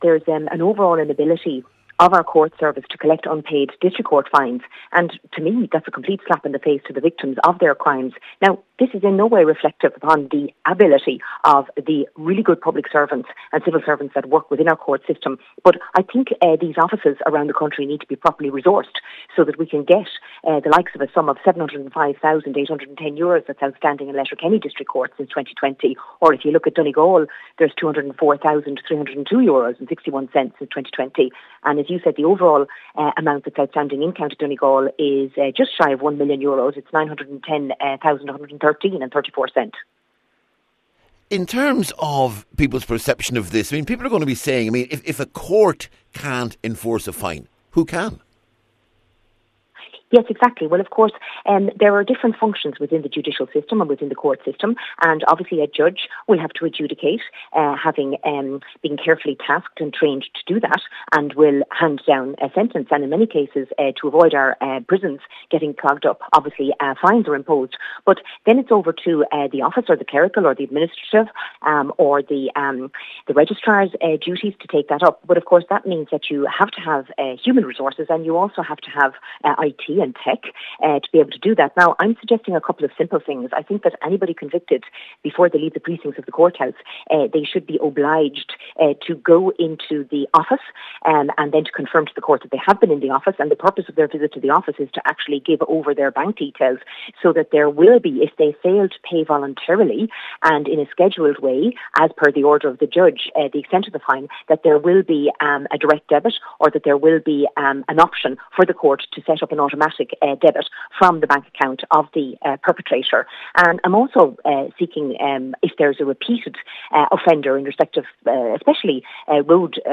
The figures were released under the Freedom of Information Act to Ireland South MEP Cynthia Ní Mhurchú, a former barrister, who says the amount of unpaid fines is a slap in the face to the victims of crime………………